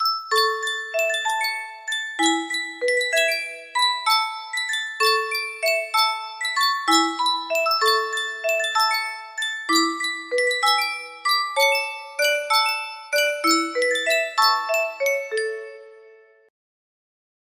Sankyo Music Box - Molly Malone REW
Full range 60